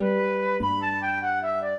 flute-harp
minuet2-4.wav